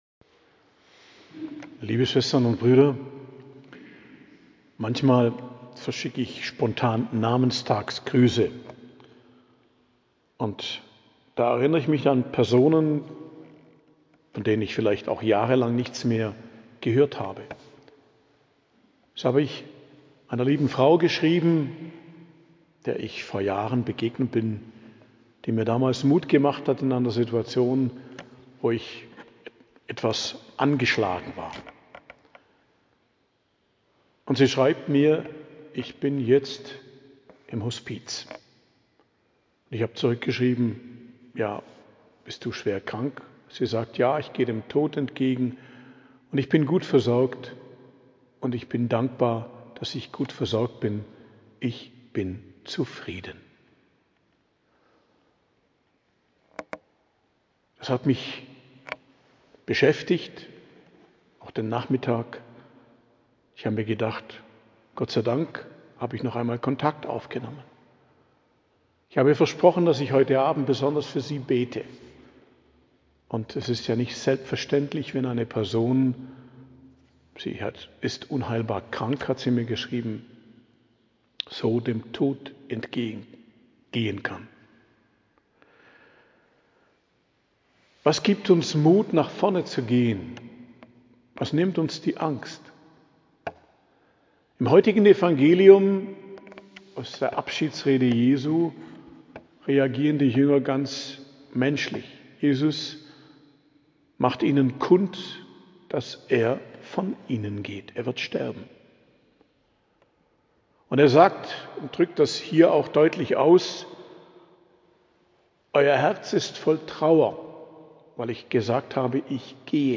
Predigt am Dienstag der 6. Osterwoche, 27.05.2025 ~ Geistliches Zentrum Kloster Heiligkreuztal Podcast